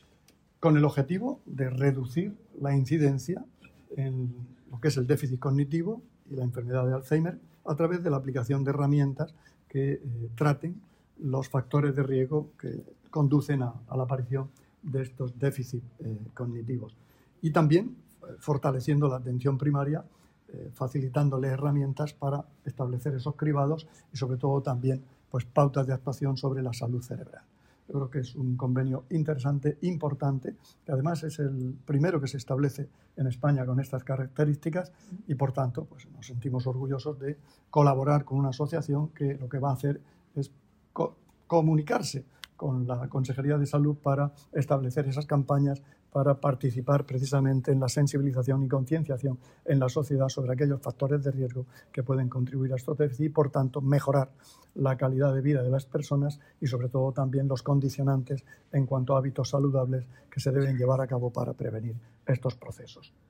Sonido/ Declaraciones del consejero de Salud, Juan José Pedreño, sobre el convenio suscrito hoy con Afade.